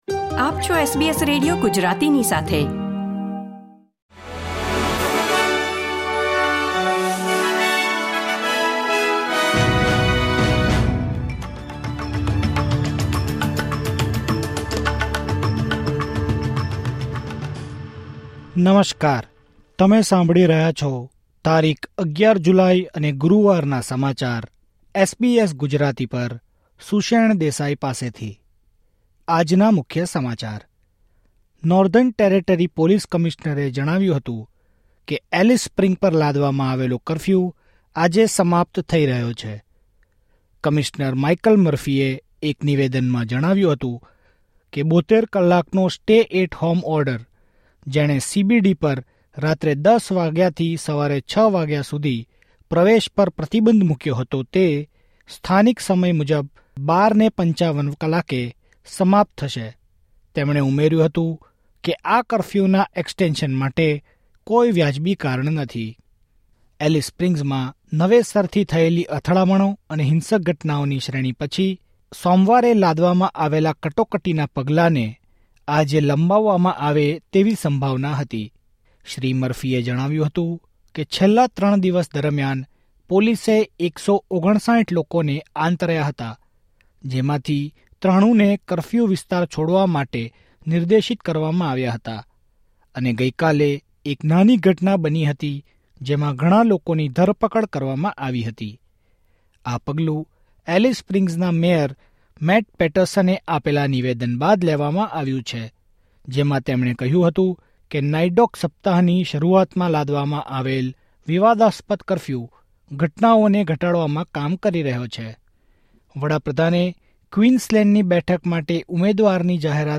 SBS Gujarati News Bulletin 11 July 2024